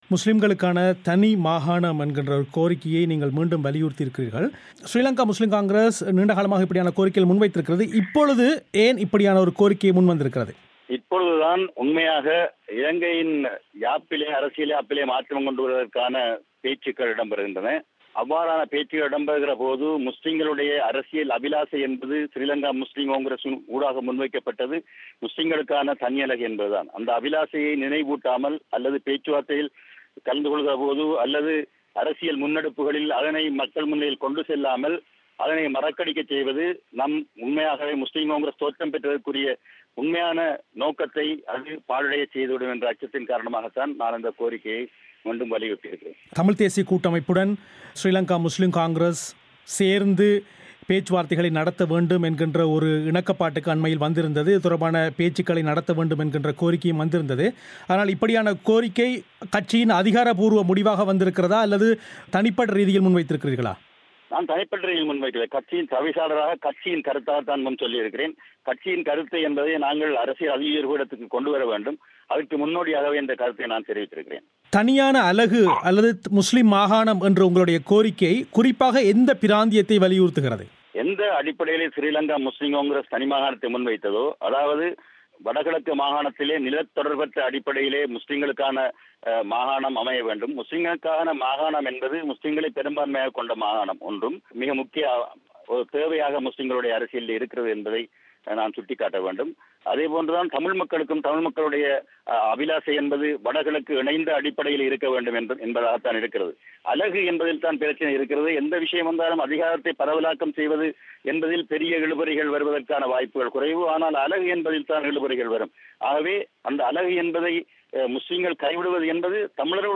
ஸ்ரீலங்கா முஸ்லிம் காங்கிரஸின் தவிசாளர் பசீர் சேகுதாவூத் பிபிசி தமிழோசைக்கு அளித்த செவ்வியின் முழுமையான ஒலி வடிவத்தை நேயர்கள் இங்கு கேட்கலாம்.